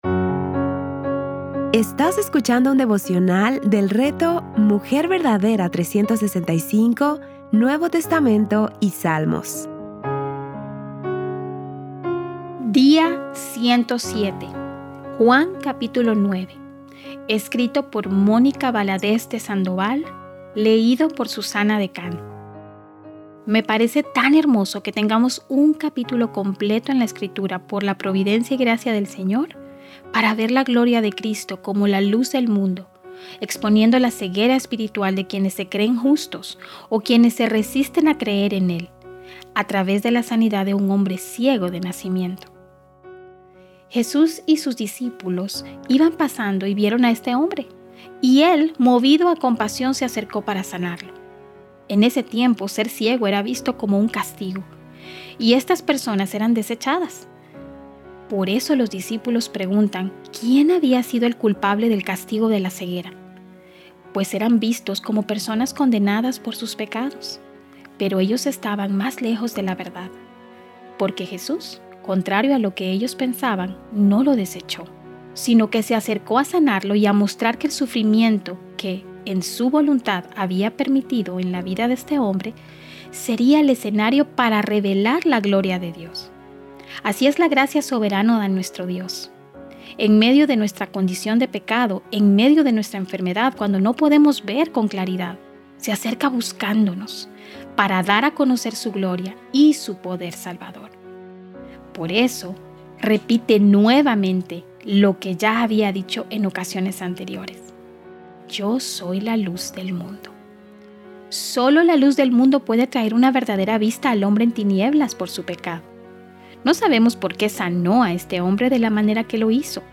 Series:  Juan y Salmos | Temas: Lectura Bíblica